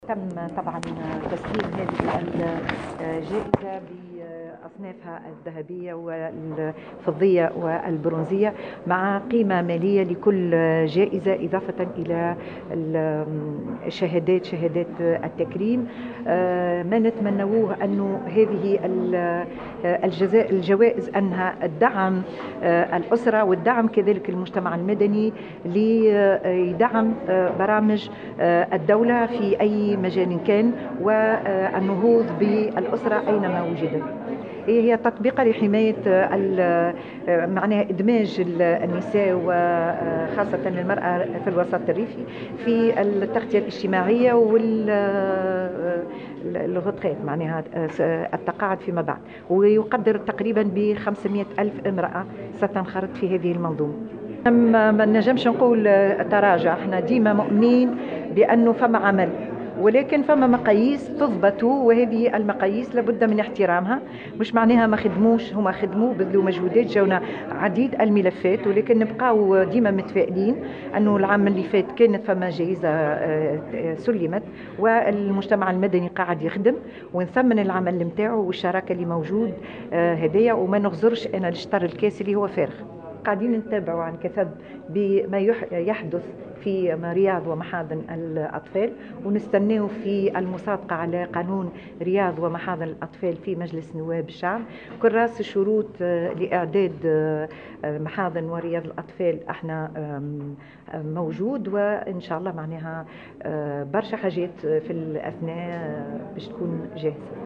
وفي سياق آخر أشارت الوزيرة في تصريح لـ "الجوهرة اف أم" إلى تطبيقة لحماية ادماج النساء خاصة في الوسط الريفي بخصوص التغطية الاجتماعة والتقاعد. كما أفادت بأن هناك مشروع قانون حول رياض ومحاضن الأطفال وأن وزارتها بصدد انتظار المصادقة عليه في مجلس نواب الشعب .